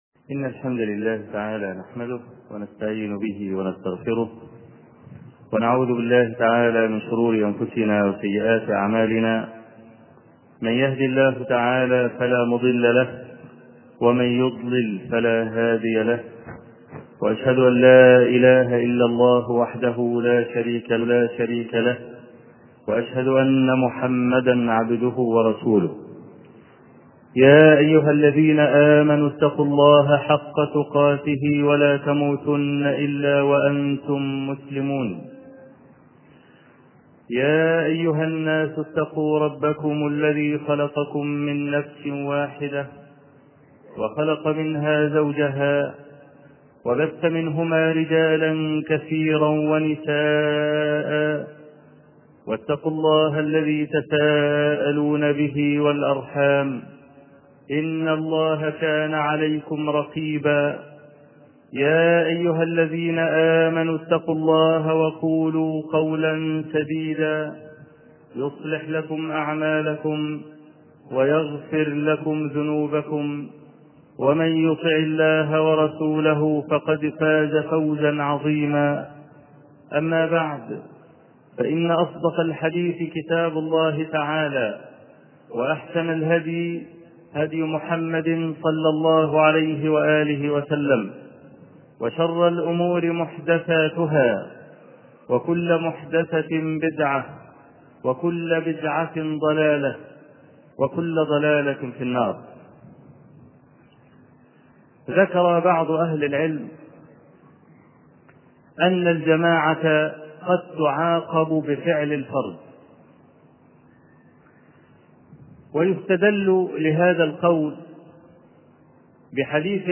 خطب ومحاضرات